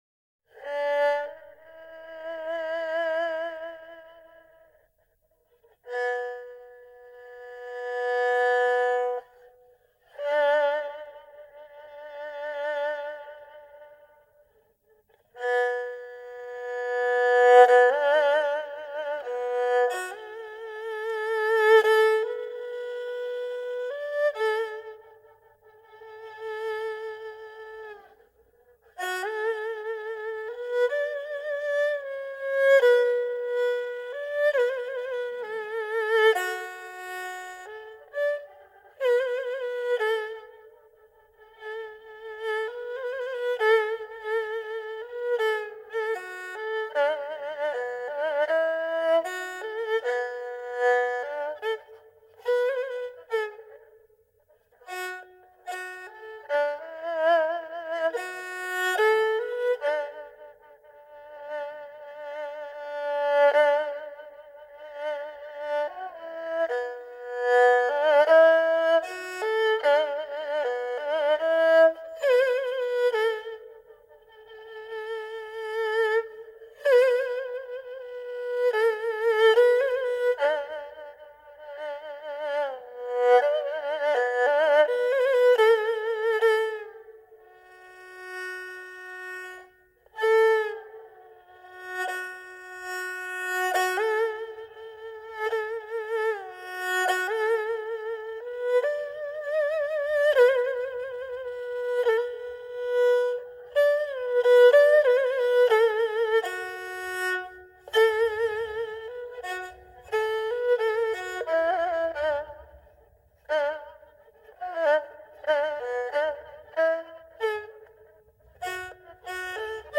二胡演奏